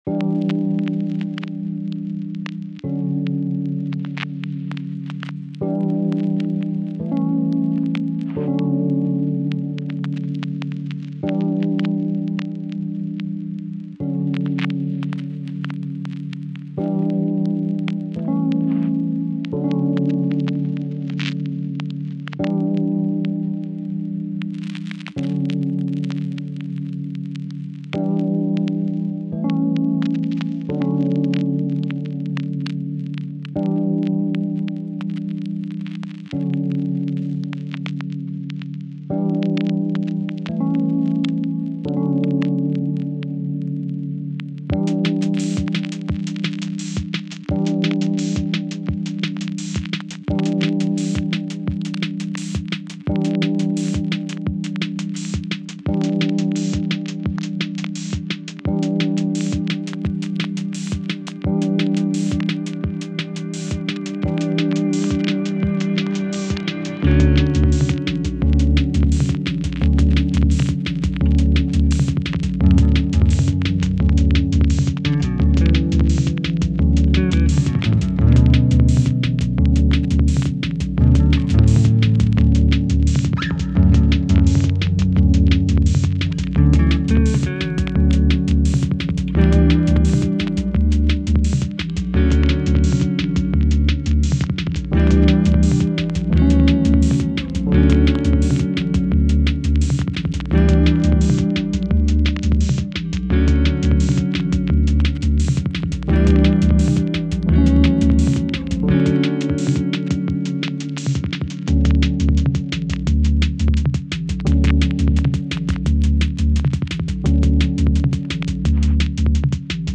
LO-LIFE-HI-STAKES-A4-lofi-.mp3